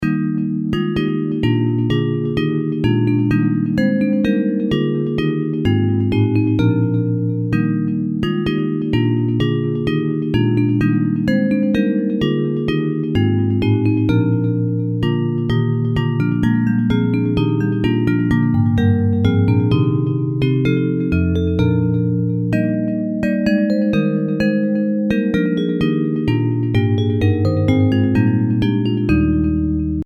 Bells Version
Music by: Croatian folk song;